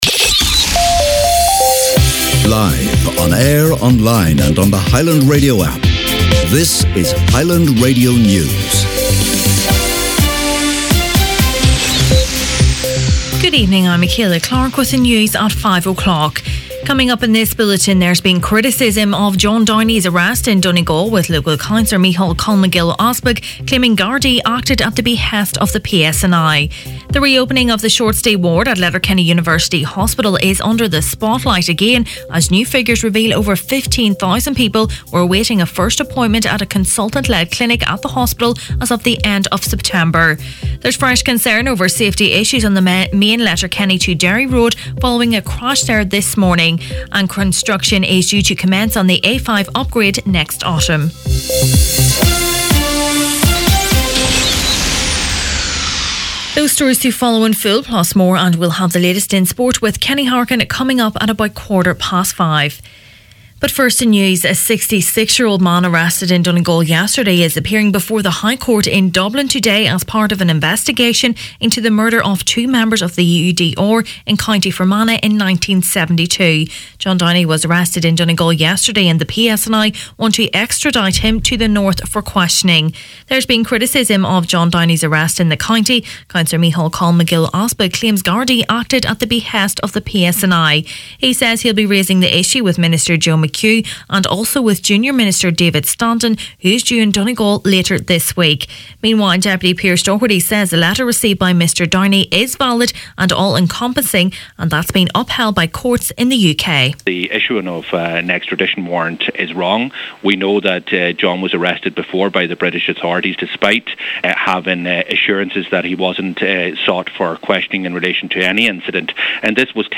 Main Evening News, Sport and Obituaries Tuesday November 6th